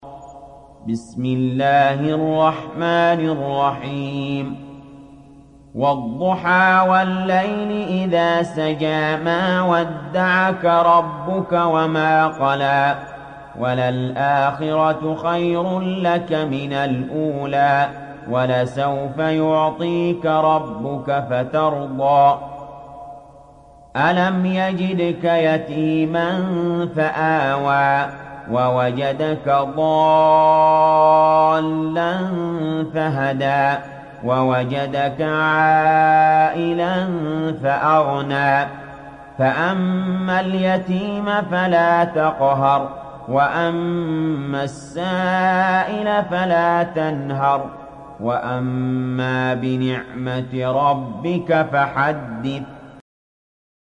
Duha Suresi İndir mp3 Ali Jaber Riwayat Hafs an Asim, Kurani indirin ve mp3 tam doğrudan bağlantılar dinle